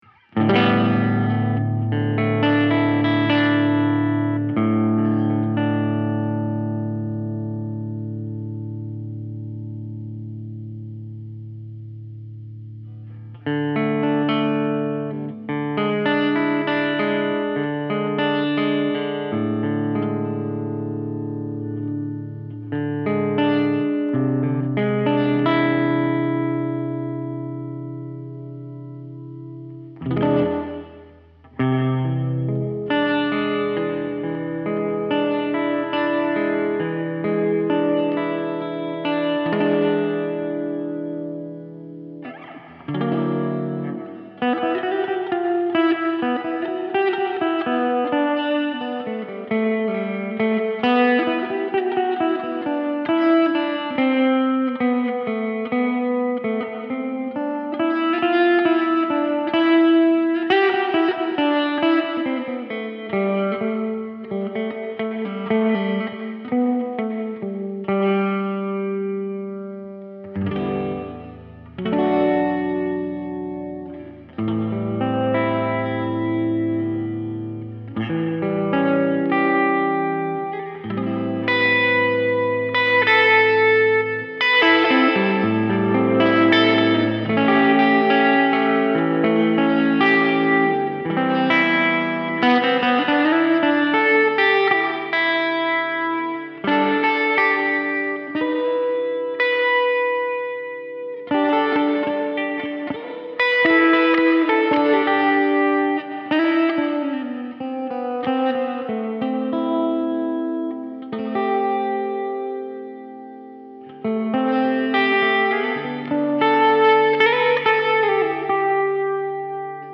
here’s a little snippet of improv guitar playing from this morning into the Fender Mustang Micro recorded into my DAW via an 1/8" cable via the headphone out.
Guitar is an Epiphone Les Paul, amp sim used on the FMM is number 2 “65 Deluxe” with the Hall Reverb. A little bit of EQ applied in Logic afterwards taking out some of the lows and some sparkly highs.